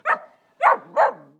Ladridos de un perro pequeño 01